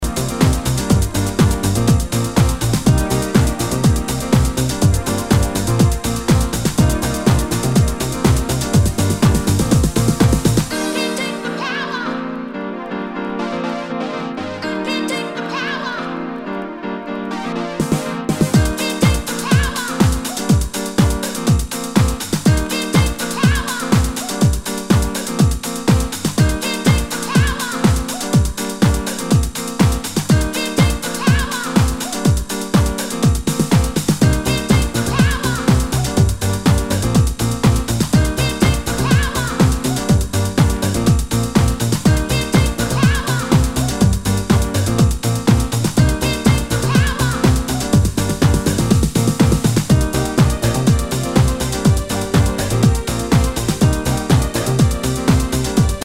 HOUSE/TECHNO/ELECTRO
ナイス！ユーロ・ハウス・クラシック！
全体にチリノイズが入ります